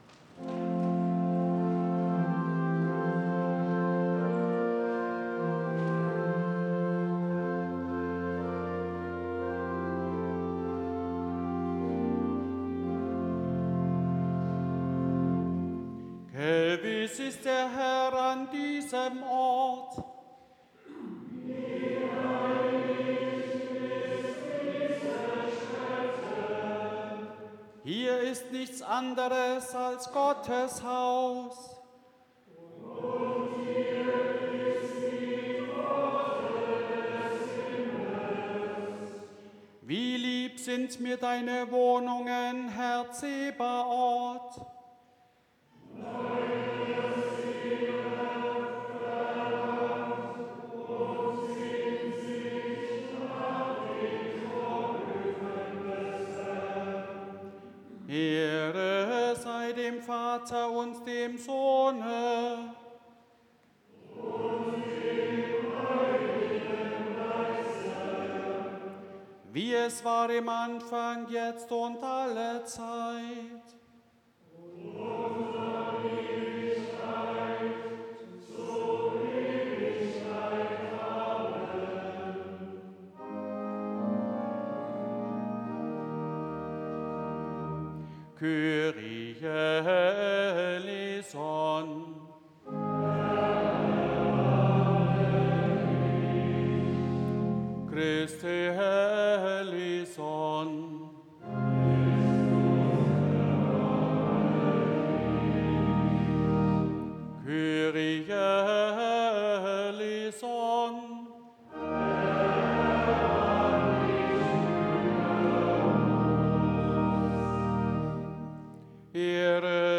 4. Eingangsliturgie Ev.-Luth. St. Johannesgemeinde Zwickau-Planitz
Audiomitschnitt unseres Gottesdienstes vom Kirchweihfest 2024